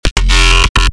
bass house one shots
Vortex_Basses_F#_7